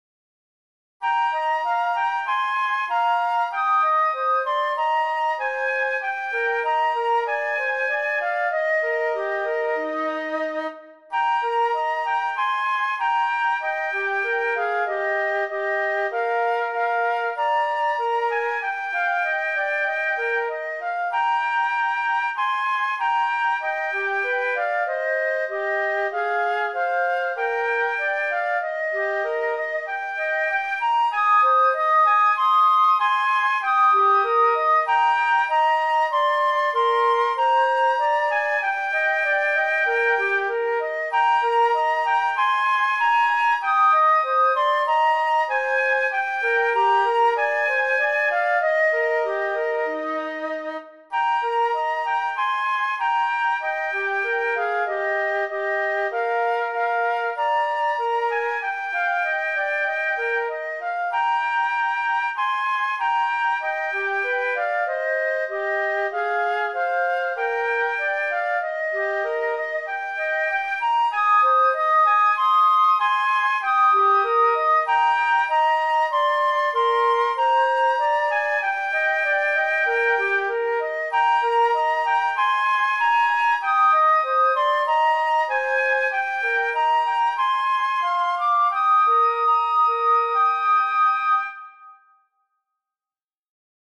フルート二重奏（フルート2本）
前奏あり。最後はセンス良くゆっくりにして終わりにしましょう・・・。